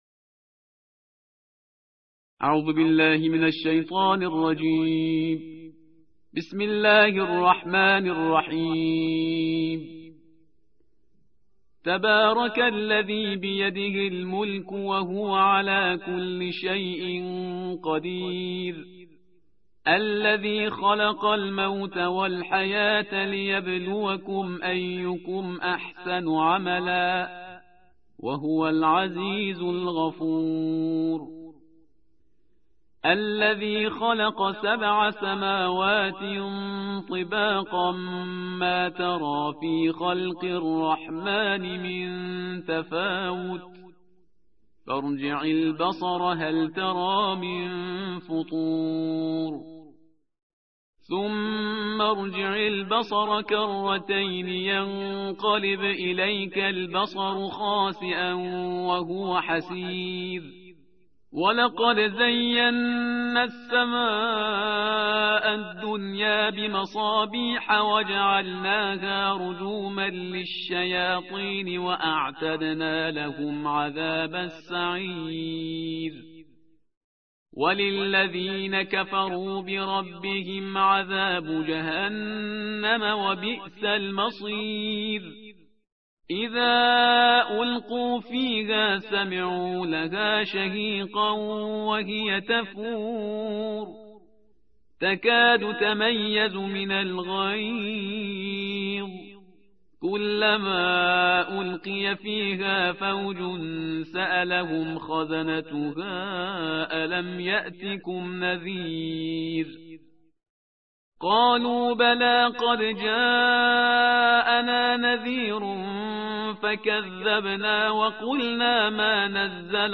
ترتیل جزء بیست و نهم قرآن کریم